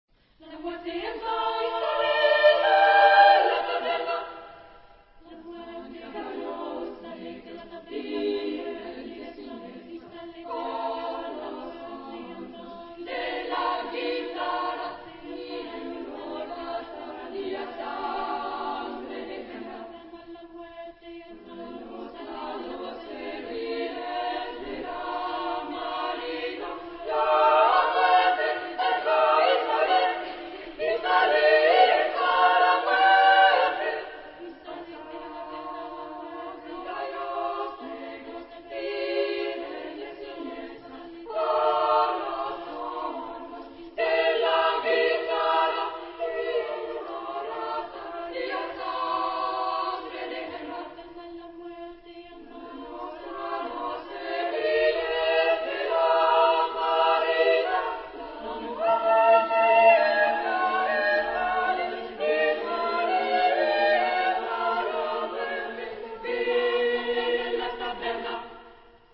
Genre-Style-Form: Secular ; Poetical song ; Choral song
Type of Choir: SSAA  (4 children OR women voices )
Tonality: E tonal center